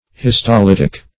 Search Result for " histolytic" : The Collaborative International Dictionary of English v.0.48: Histolytic \His`to*lyt"ic\, a. (Biol.)